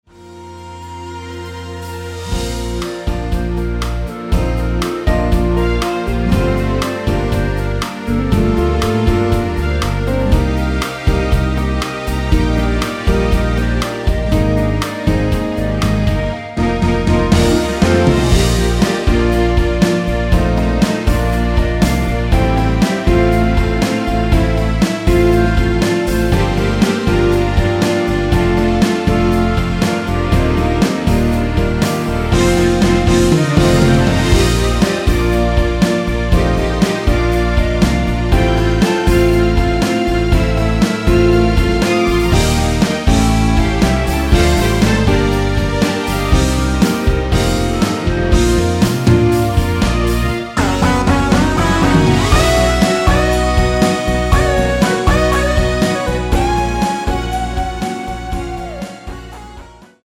원키에서(-1)내린 멜로디 포함된 MR입니다.
Bb
앞부분30초, 뒷부분30초씩 편집해서 올려 드리고 있습니다.
중간에 음이 끈어지고 다시 나오는 이유는